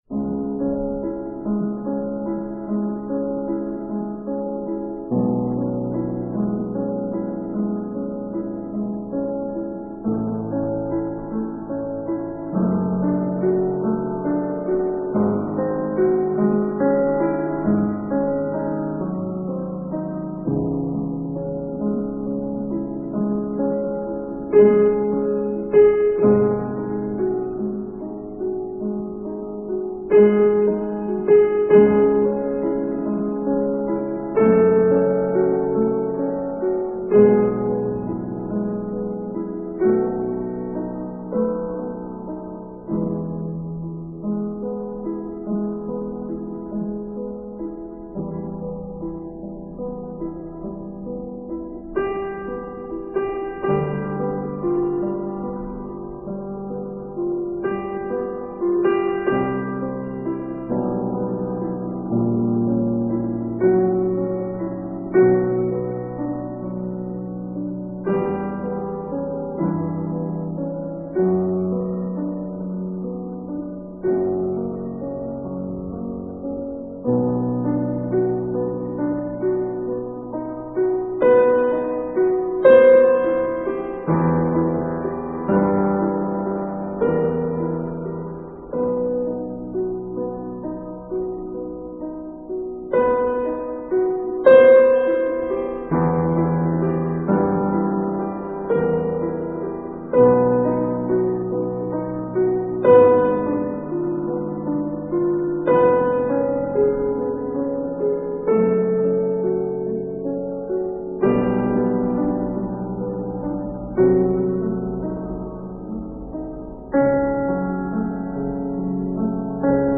Бетховен_-_Лунная_соната_(Соната_для_фортепиано_N14).ogg